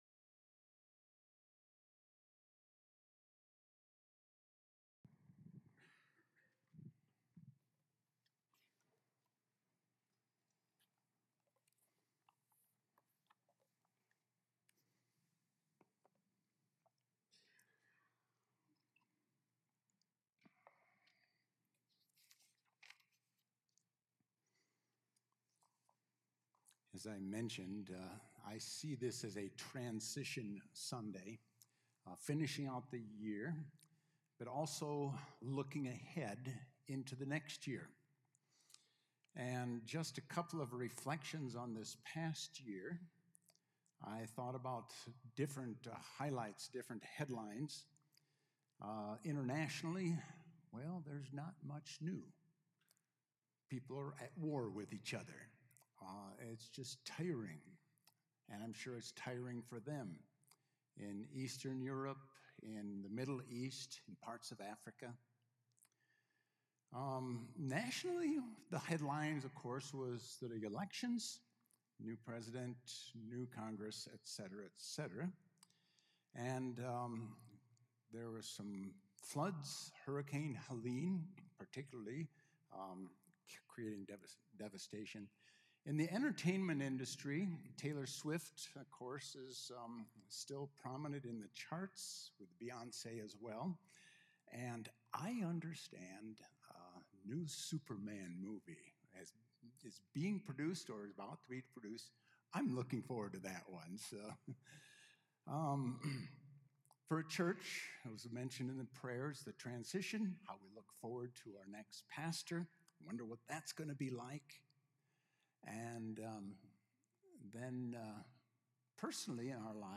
A message from the series "Advent 2024."